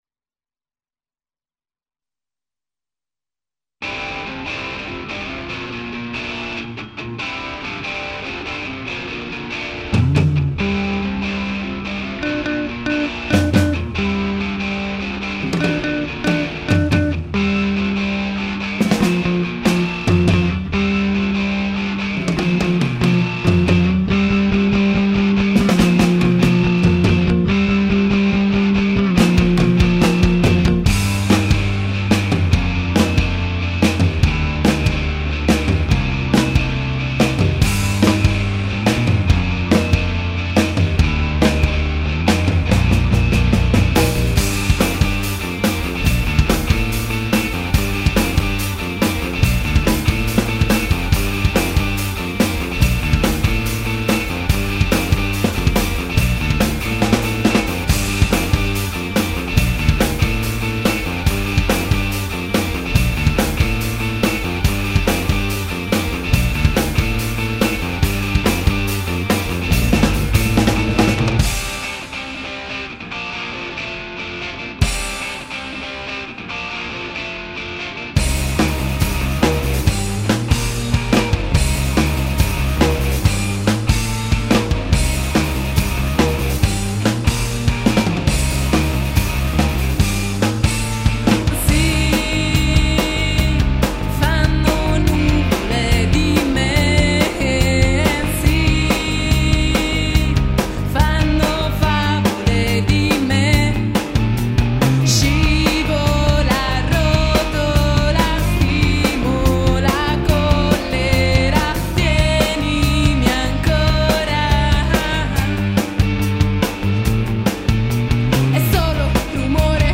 studioDemo - maggio2005